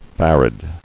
[far·ad]